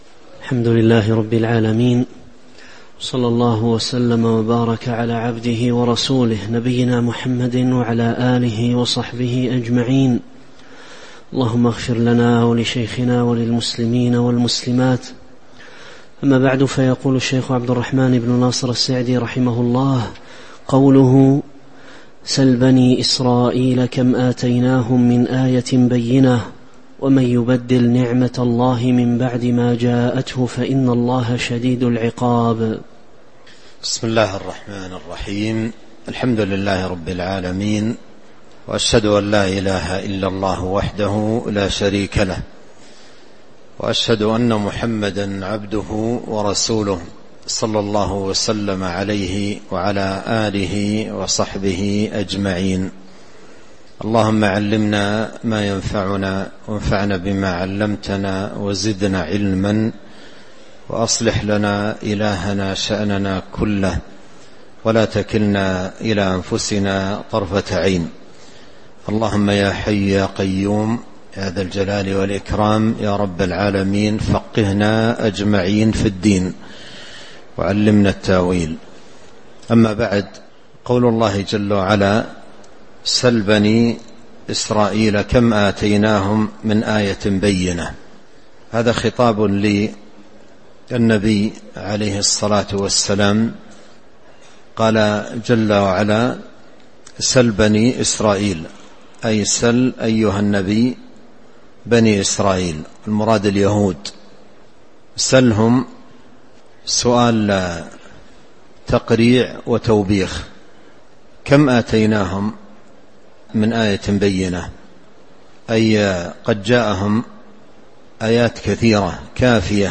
تاريخ النشر ١ شعبان ١٤٤٦ هـ المكان: المسجد النبوي الشيخ